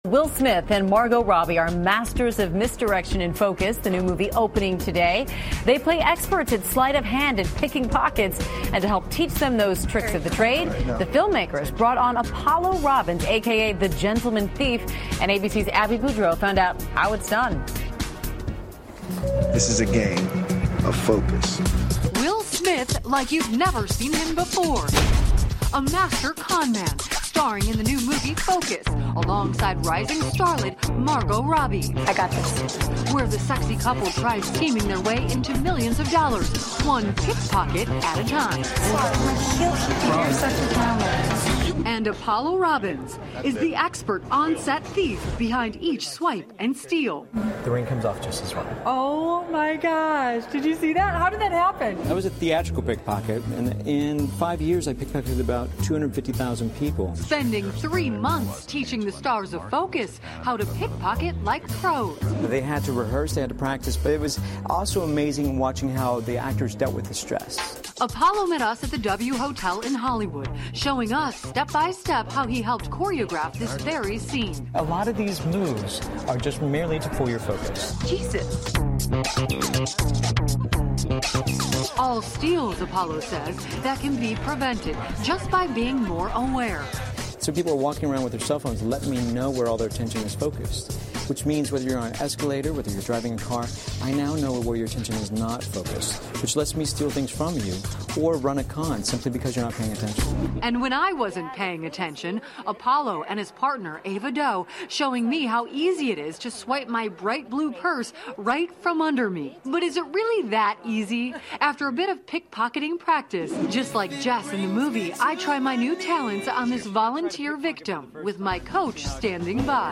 访谈录 威尔·史密斯谈新作《焦点》 神偷上身 听力文件下载—在线英语听力室